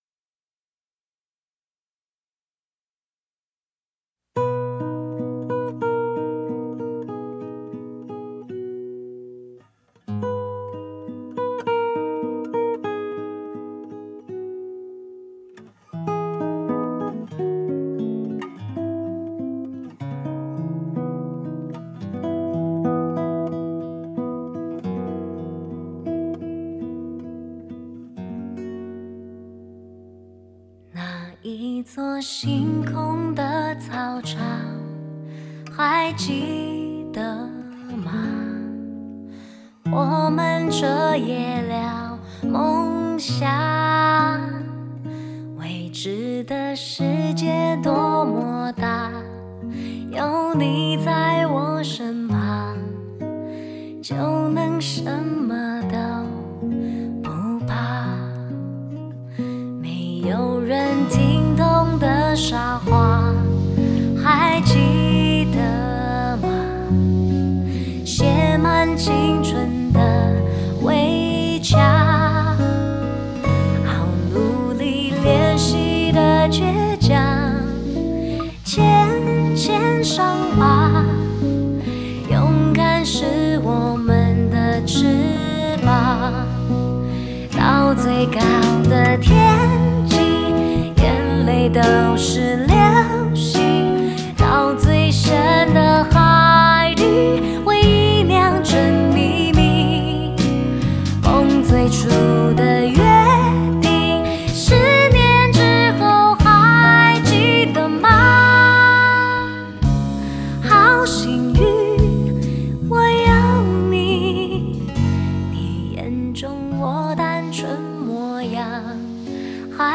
Ps：在线试听为压缩音质节选，体验无损音质请下载完整版
深情弦乐版